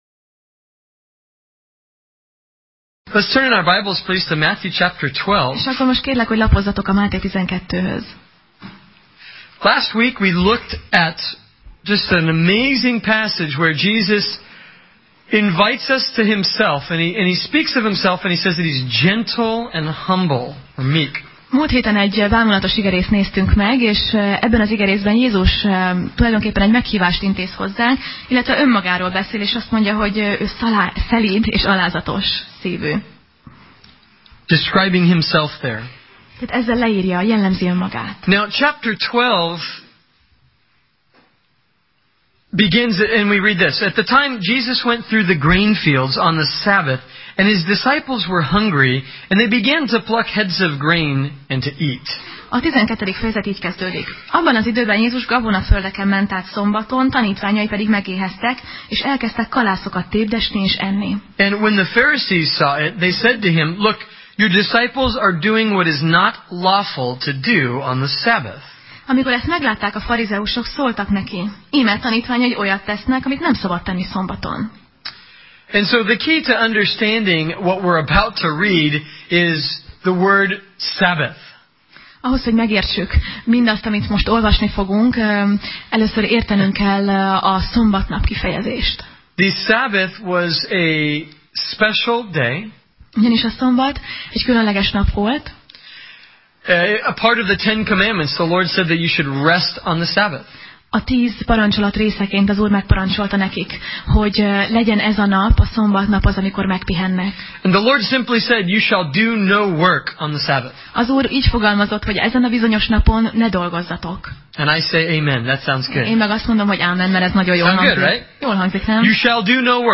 Máté Passage: Máté (Matthew) 12:1–13 Alkalom: Vasárnap Reggel